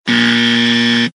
Buzzer Sound Effect Free Download
Buzzer